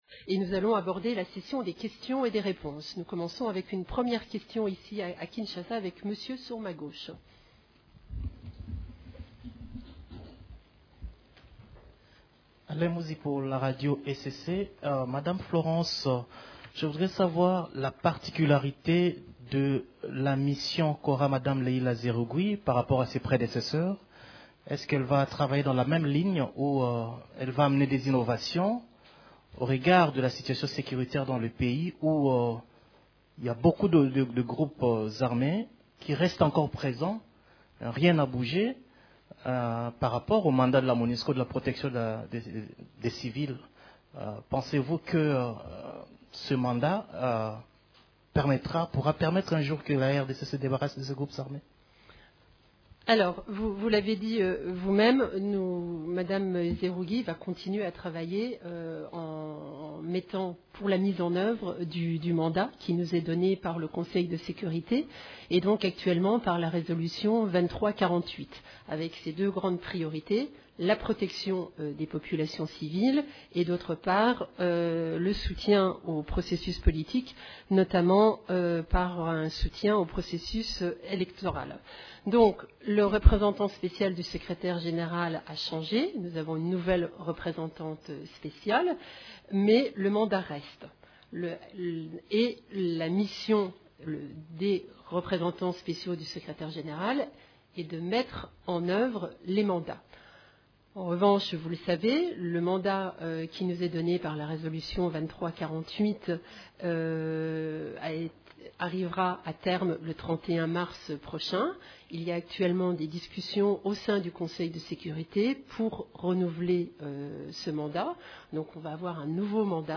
Conférence de presse du mercredi 14 février 2018
Les activités d’agences des Nations unies en RDC ont été au centre de la conférence de presse hebdomadaire de l’ONU (en vidéo) à Kinshasa et à Goma.
Ecoutez la première partie de cette conférence de presse: